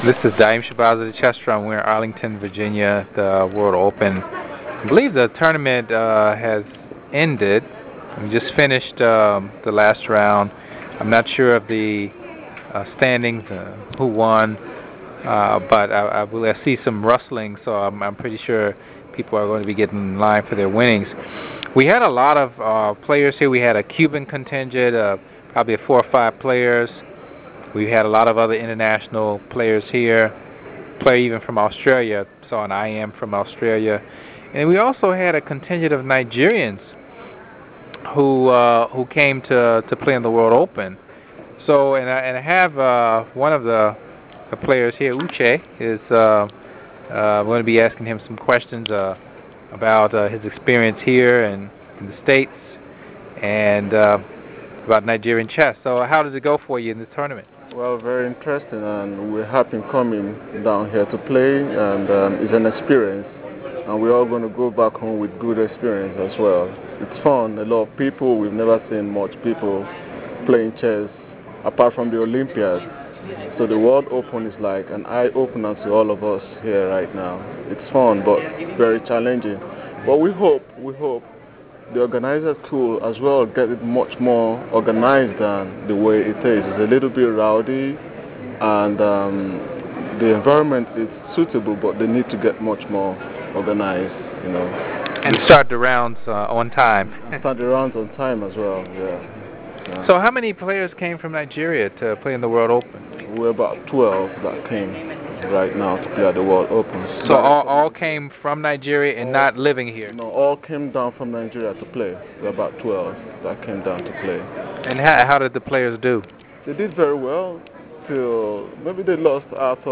The Chess Drum was able to conduct some very interesting interviews during the tournament.